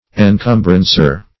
Encumbrancer \En*cum"bran*cer\, n.